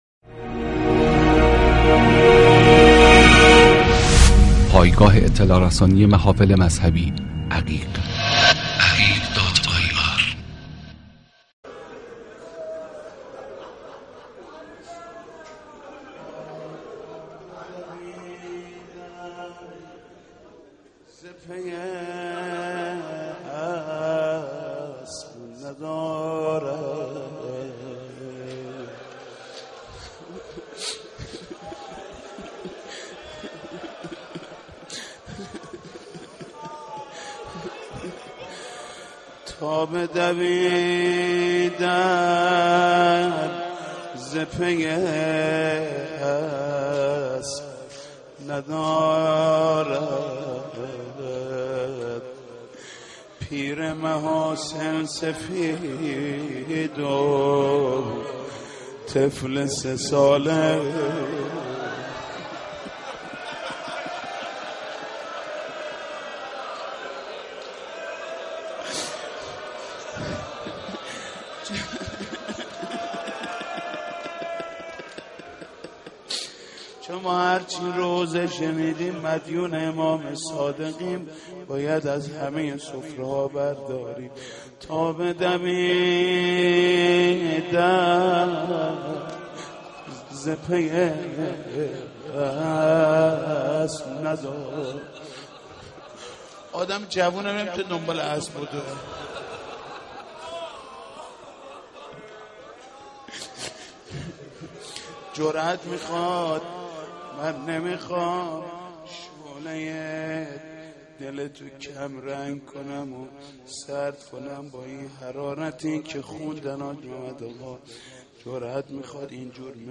عقیق: مراسم شب شهادت امام صادق(ع) با حضور خیل عزادارن و عاشقان خاندان عصمت و طهارت یکشنبه شب در مسجد الهادی (ع) واقع در مجیدیه شمالی برگزار شد.
روضه / حاج محمود کریمی